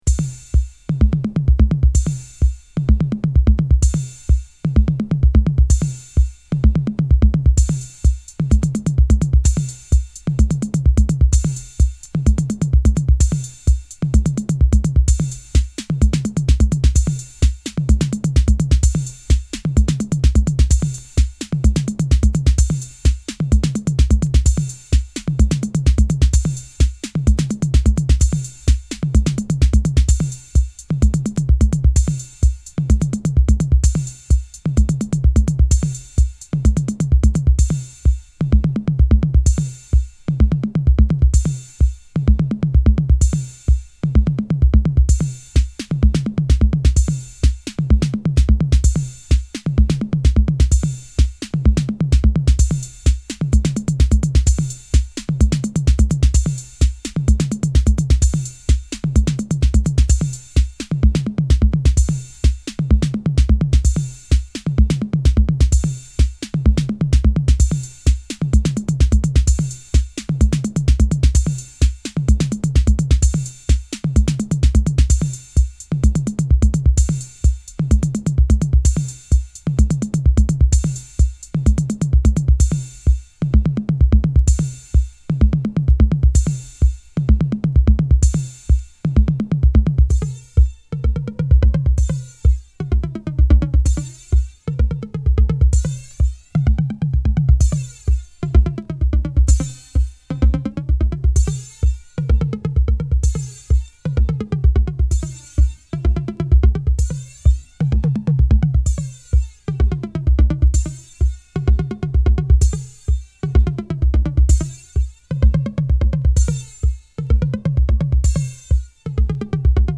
Acid House
Chicago House